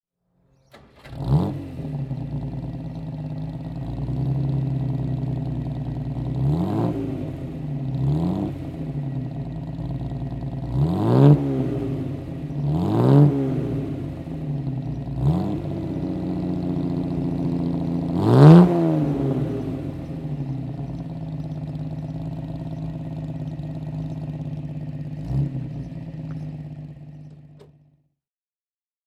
AC Ace Bristol (1960) - Starten und Leerlauf
AC_Ace_Bristol_1960.mp3